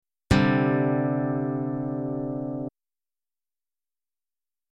Suspended 2 1-2-5 Root-M2-P5
csus2.mp3